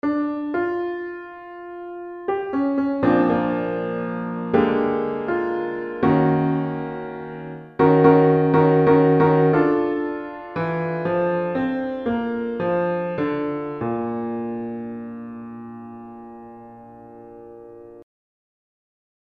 Key written in: B♭ Major
How many parts: 4
All Parts mix:
synth voices & a piano